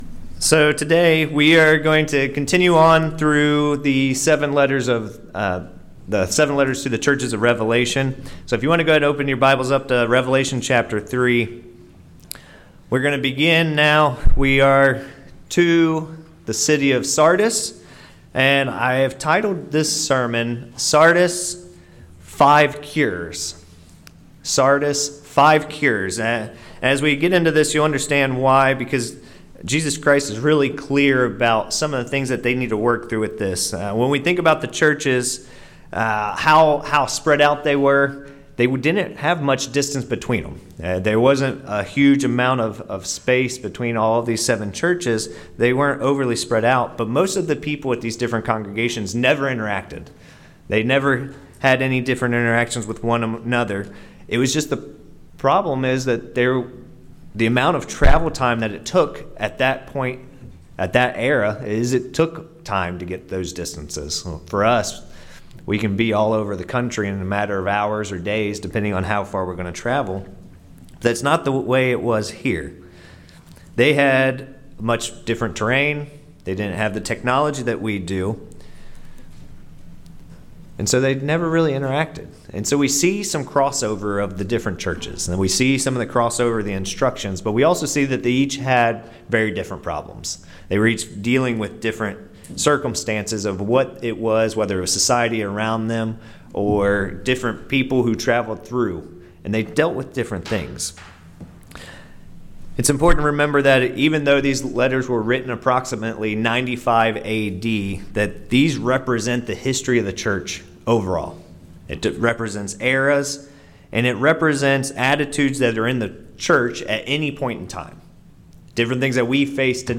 The Sardis church was losing track of God's plan. This sermon covers five cures to the troubles the Sardis church was having.